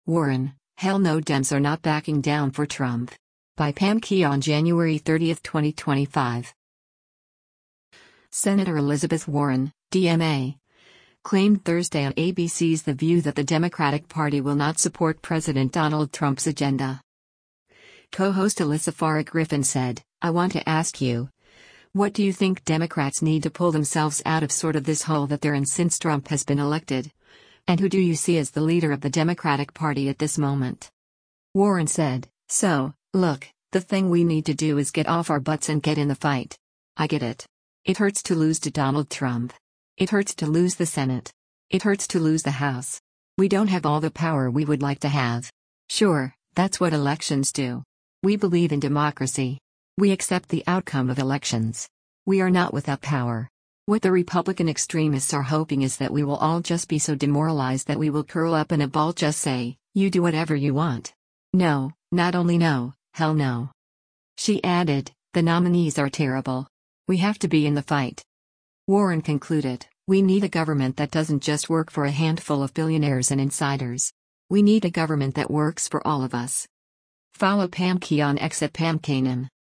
Senator Elizabeth Warren (D-MA) claimed Thursday on ABC’s “The View” that the Democratic Party will not support President Donald Trump’s agenda.